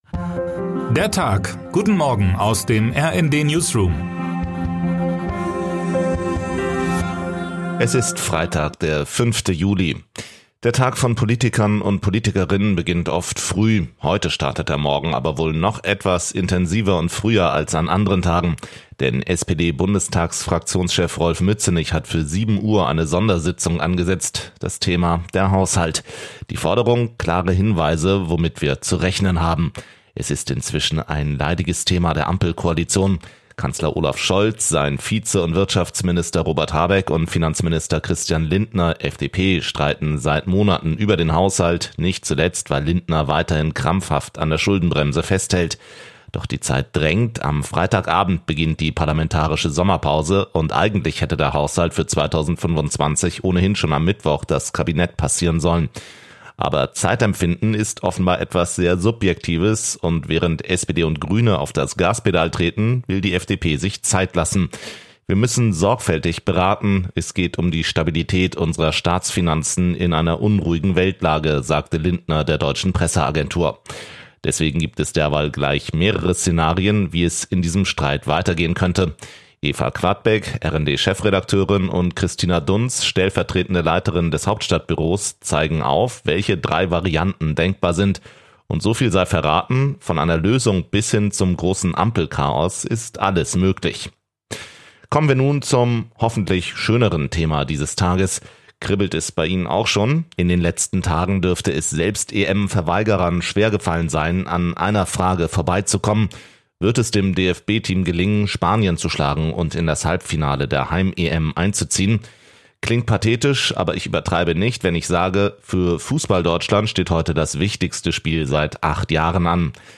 Guten Morgen aus dem RND-Newsroom
Nachrichten